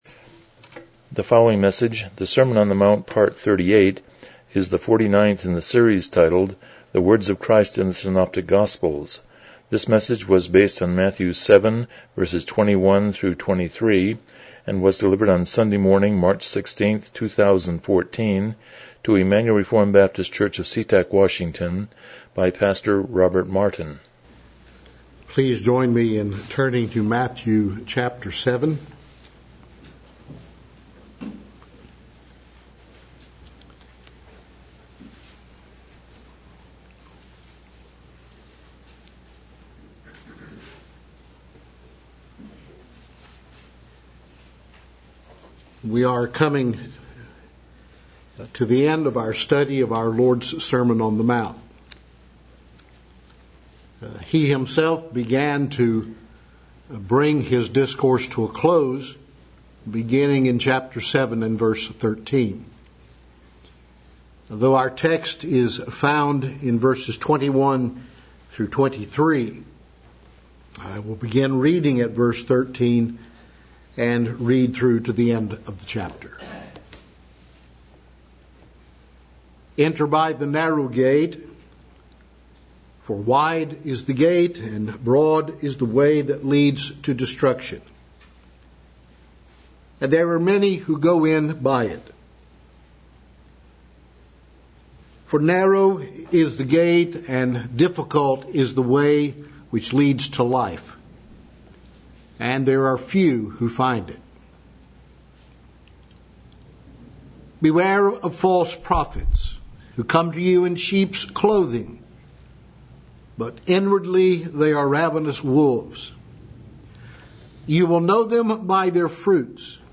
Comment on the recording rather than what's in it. The Words of Christ in the Synoptic Gospels Passage: Matthew 7:21-23 Service Type: Morning Worship « 32 The Sovereignty of God